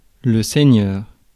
Ääntäminen
France (Paris): IPA: [sɛ.ɲœʁ]